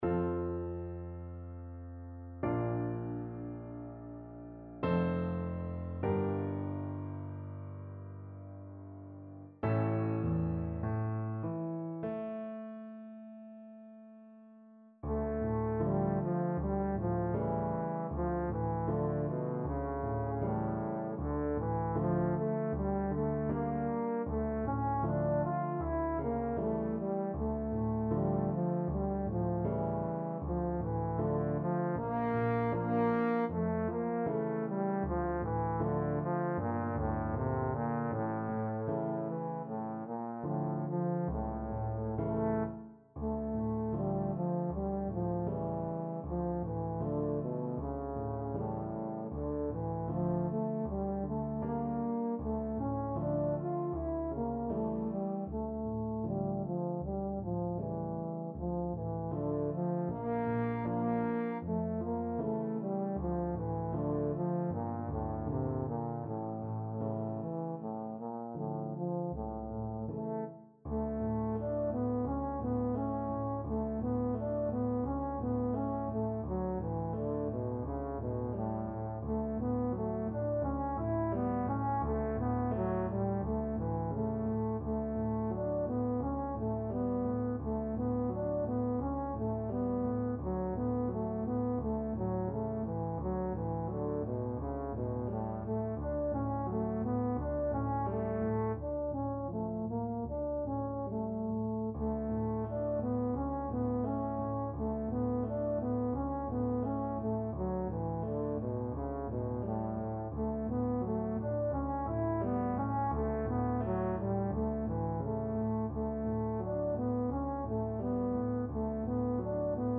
Free Sheet music for Trombone
F major (Sounding Pitch) (View more F major Music for Trombone )
Largo
4/4 (View more 4/4 Music)
Classical (View more Classical Trombone Music)